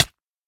should be correct audio levels.
hurtflesh1.ogg